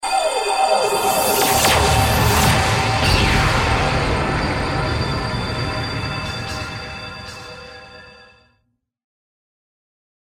SFX法术技能释放咻咻声音效下载
SFX音效